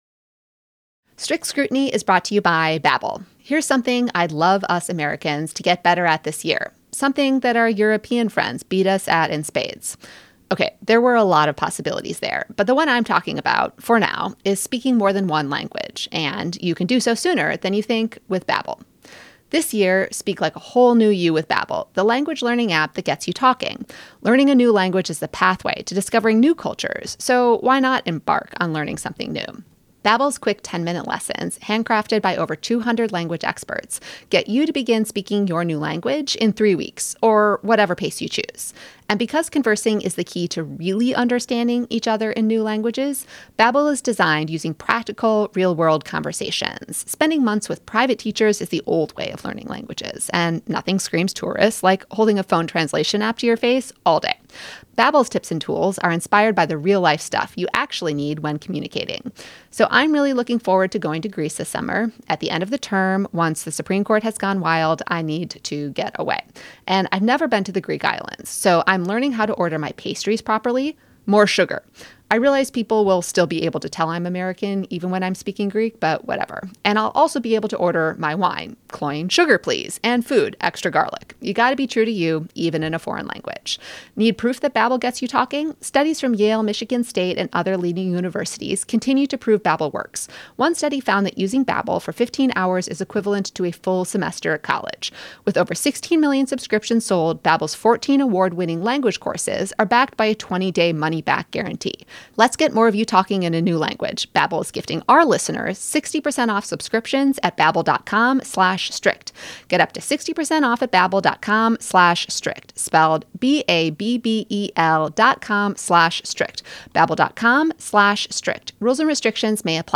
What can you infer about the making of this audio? Live from Fordham Law